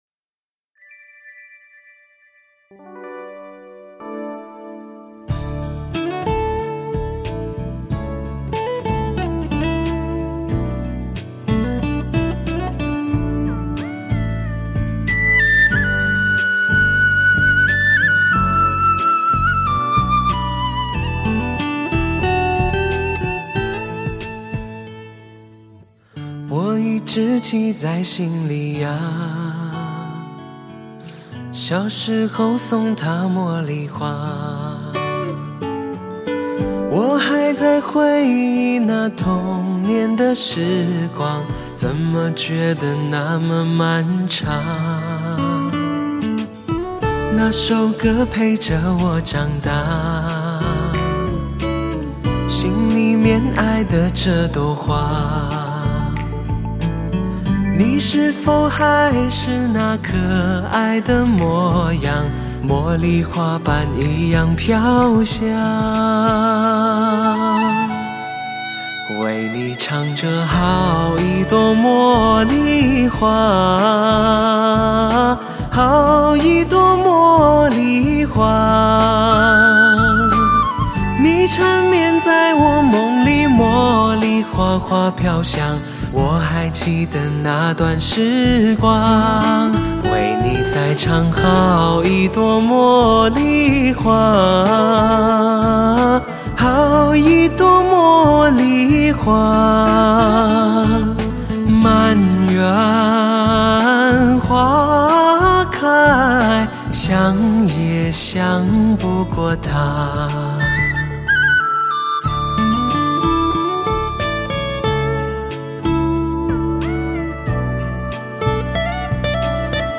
情人怀抱般温暖嗓音 非凡深情折服你我心
经典的歌谣焕然一新，无论岁月如何变迁，花瓣的清香依然如故， 童年的故事依旧如新，那份纯纯的爱依然魂萦梦牵。